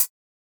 Drum_Hits
Closedhat02.wav